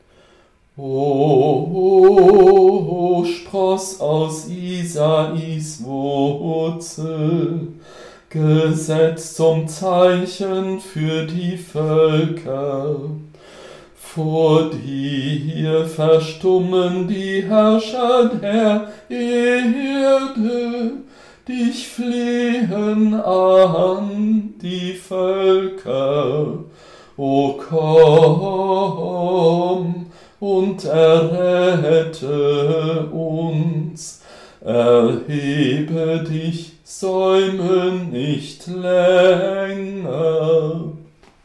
Antiphon